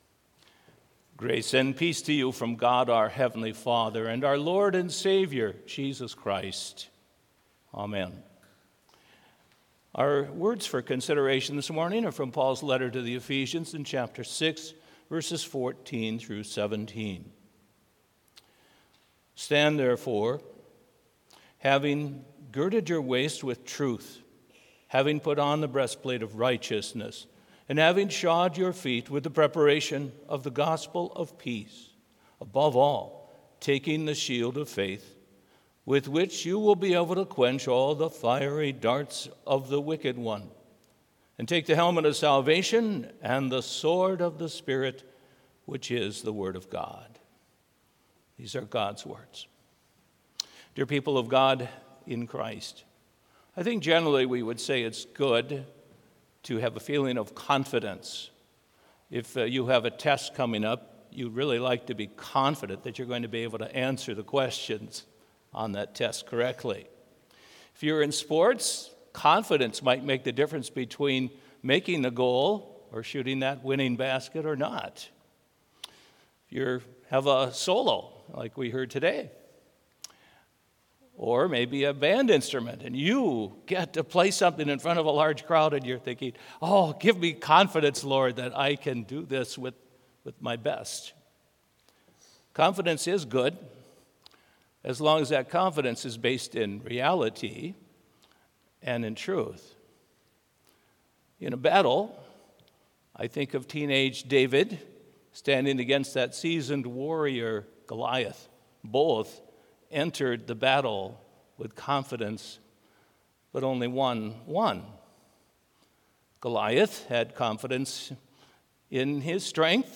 Complete service audio for Chapel - Thursday, October 24, 2024